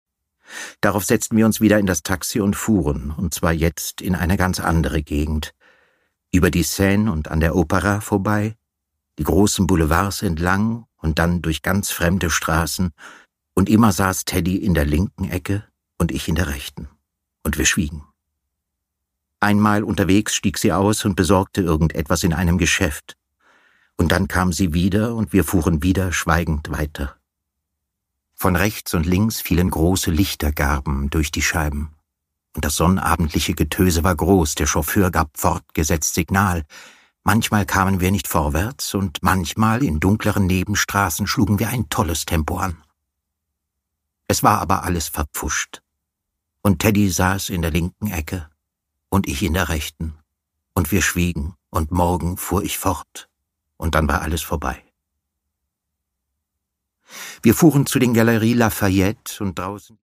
Produkttyp: Hörbuch-Download
Gelesen von: Sebastian Blomberg